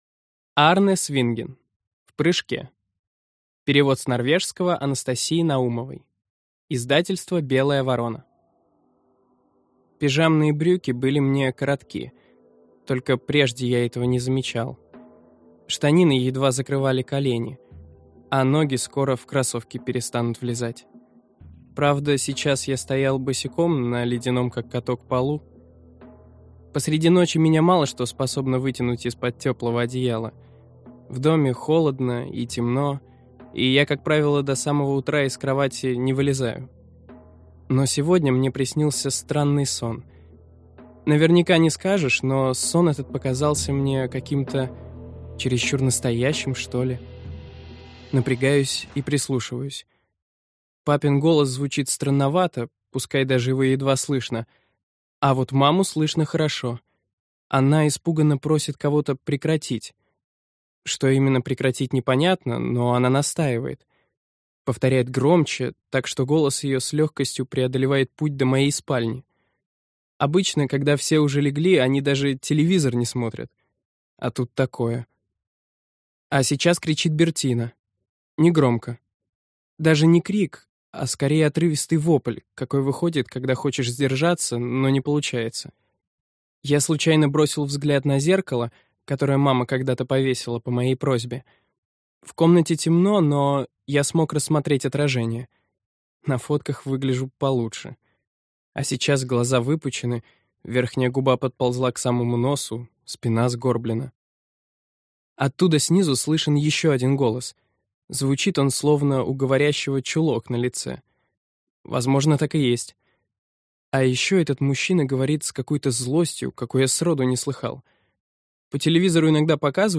Aудиокнига В прыжке